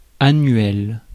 Ääntäminen
Ääntäminen France: IPA: [a.nɥɛl] Haettu sana löytyi näillä lähdekielillä: ranska Käännös Konteksti Ääninäyte Adjektiivit 1. yearly US 2. annual kasvitiede US Suku: m .